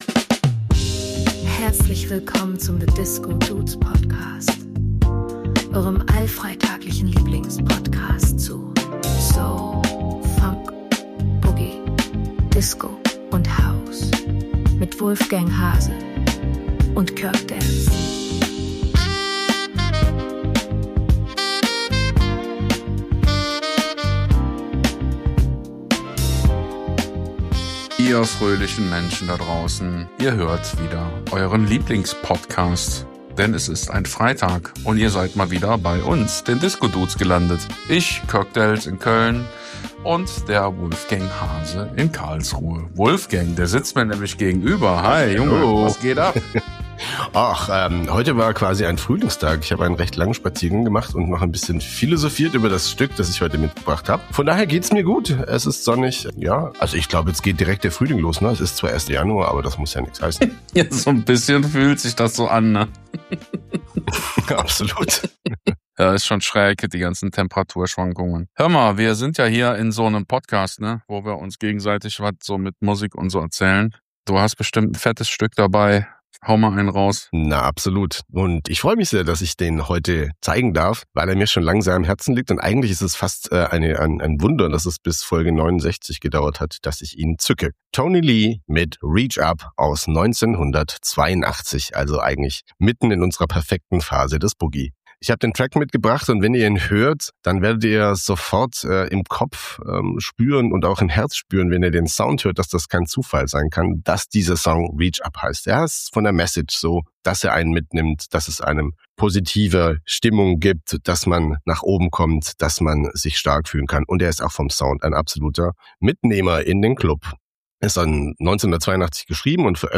New York Proto House Gospel 🪩 Disco and Pop-Boogie de Luxe